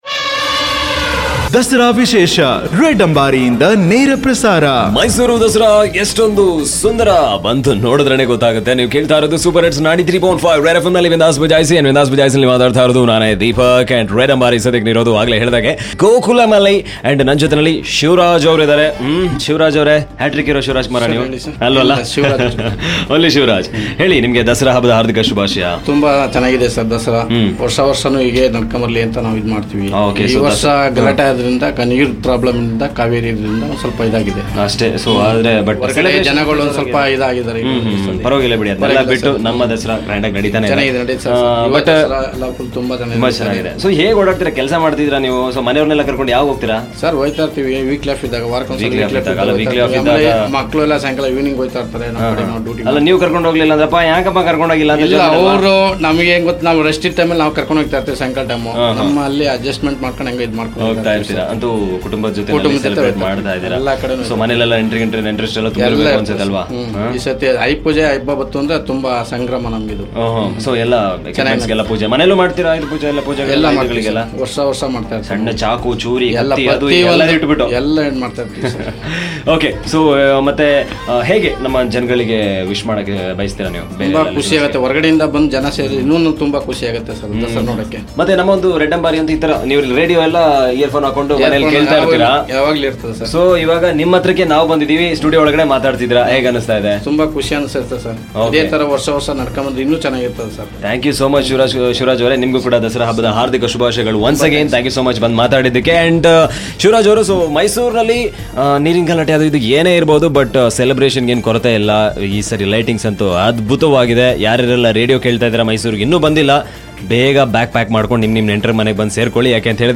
Red Ambari is a Studio on wheel where listeners and celebrities share their festive excitement